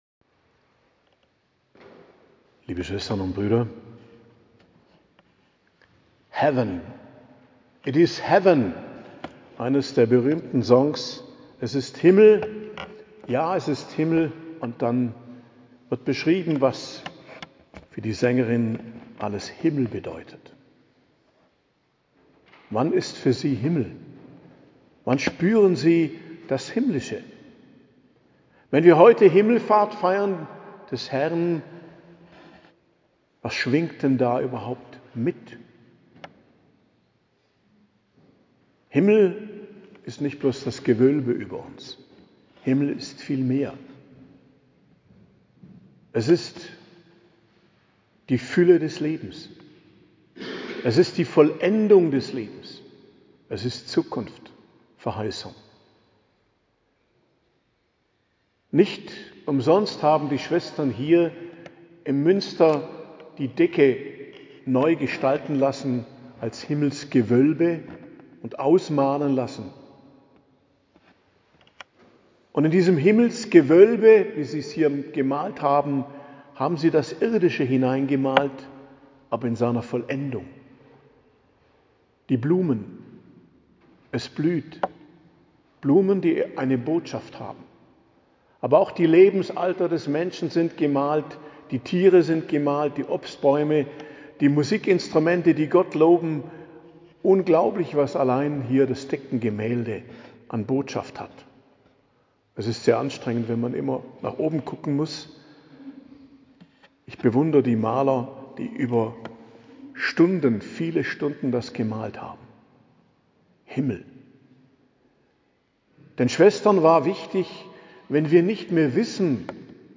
Predigt zum Hochfest Christi Himmelfahrt, 29.05.2025 ~ Geistliches Zentrum Kloster Heiligkreuztal Podcast